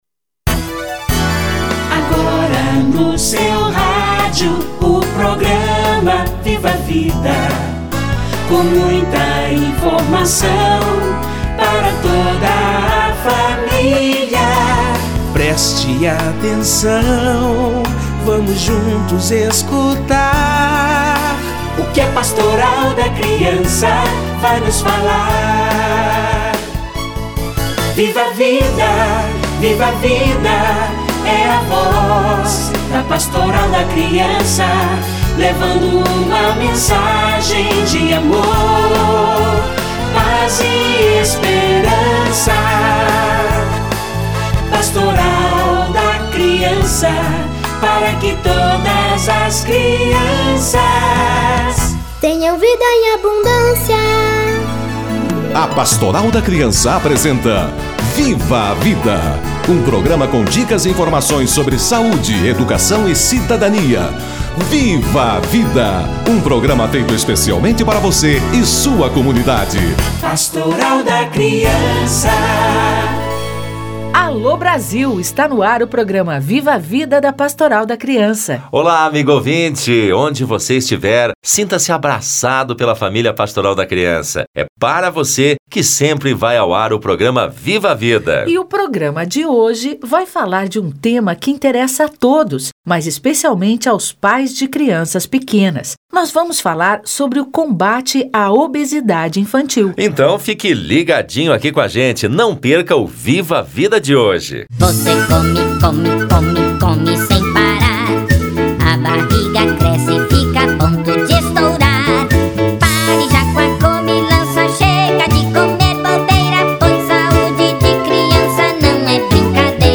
Obesidade infantil - Entrevista